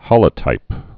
(hŏlə-tīp, hōlə-)